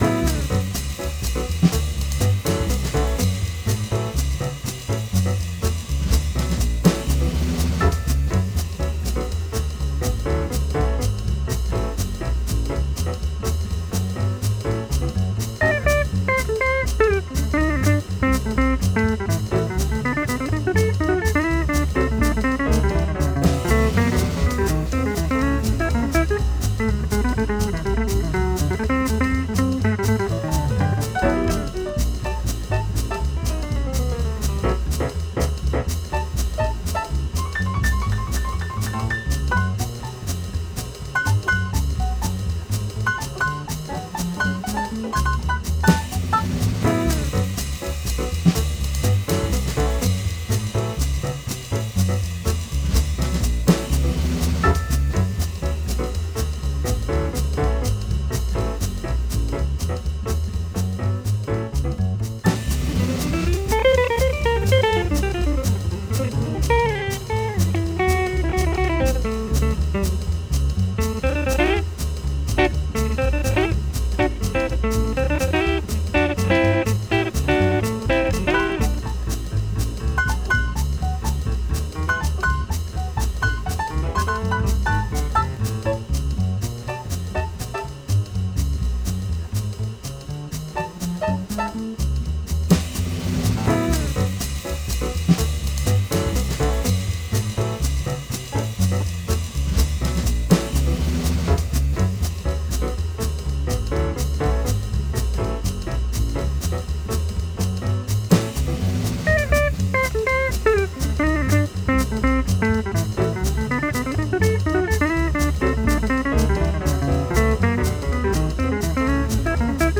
platillo
piano
jazz